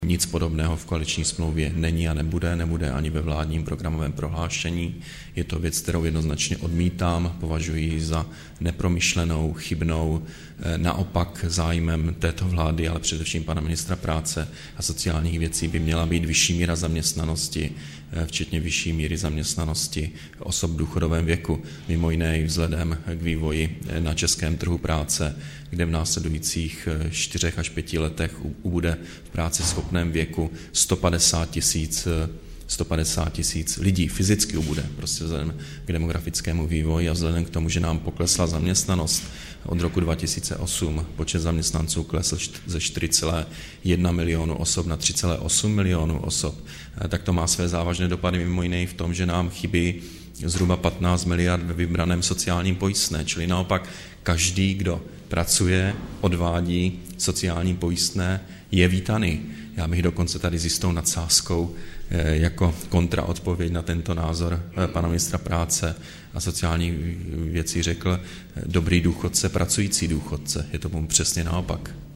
„Musím říci, že nic podobného v koaliční smlouvě není a nebude, nebude ani ve vládním programovém prohlášení. Je to věc, kterou jednoznačně odmítám, považuji ji za nepromyšlenou a chybnou,“ vysvětlil novinářům premiér.